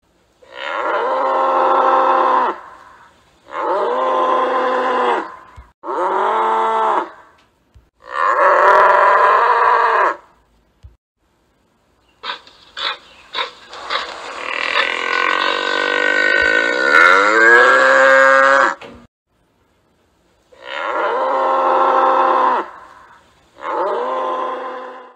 Suara Banteng
Kategori: Suara binatang liar
Suara banteng marah, raungan keras, serta suara bising yang penuh tenaga kini bisa Anda nikmati secara langsung.
suara-banteng-id-www_tiengdong_com.mp3